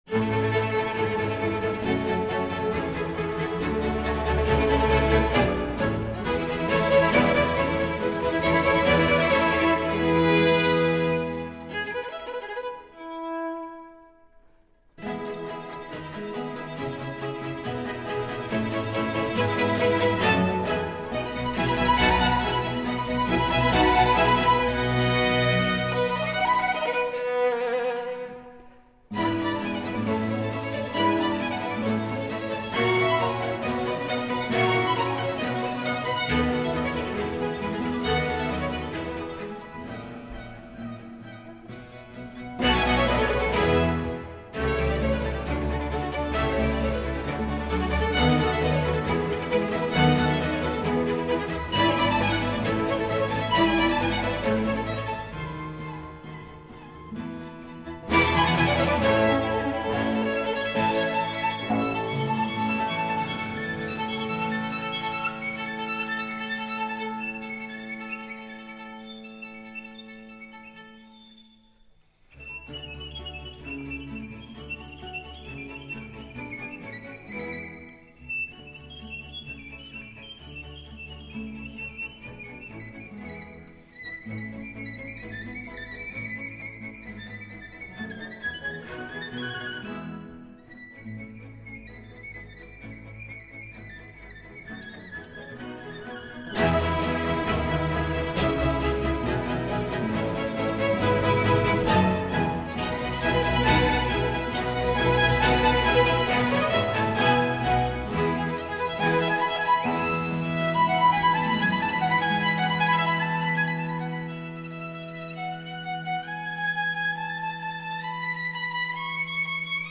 Incontro con Isabelle Faust
Artista amatissima quanto schiva e riservata, in un italiano eccellente, con leggerezza e profonda umiltà racconta delle sue scelte musicali, del sodalizio che la lega da tempo al Giardino Armonico (giunto al traguardo dei suoi primi 40 anni), del suo inseparabile compagno, lo Stradivari Sleeping beauty e, perché no, di sè.